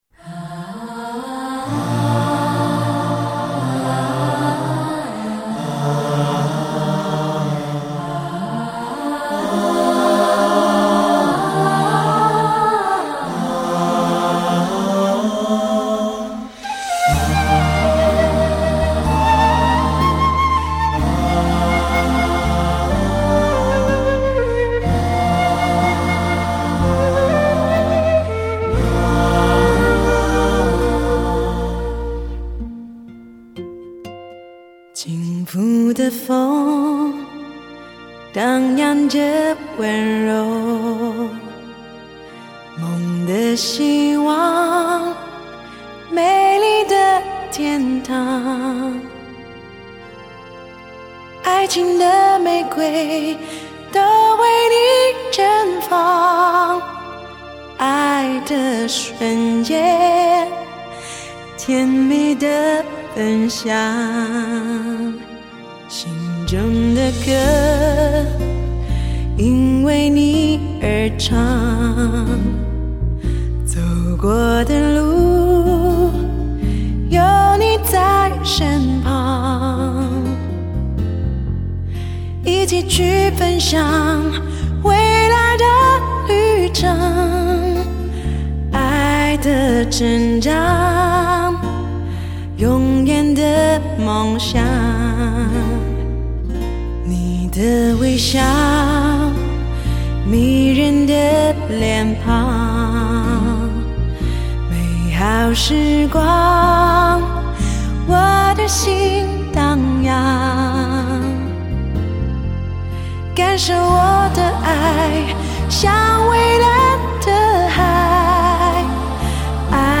中国公认好声音，游走于流行与发烧之间的最强音，
她的声音充满细致的磨砂质感，她的歌唱技巧娴熟，
游刃有余，挥洒自如，充满特色......